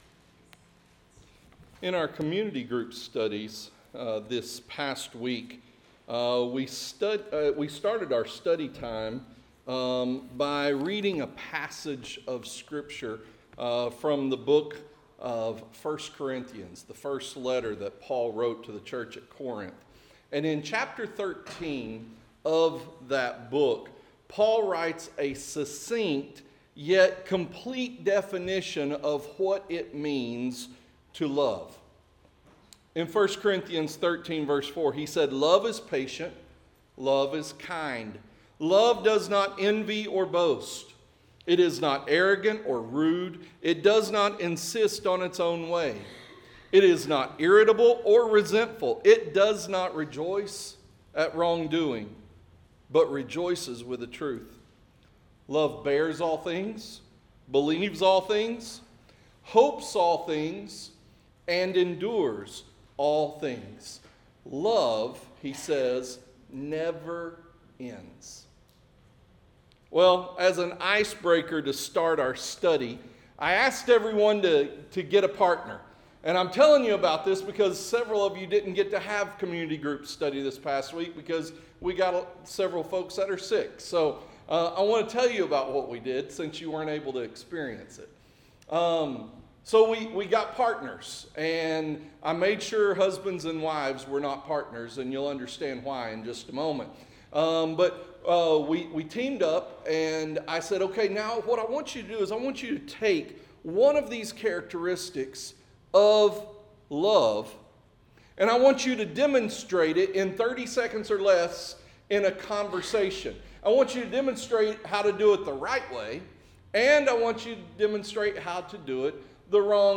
Interactive Sermon Notes Recharge – Full PDF Download Recharge – Week 7 Download Series: Nehemiah: Rebuild & Renew , REBUILD: Restoring the Broken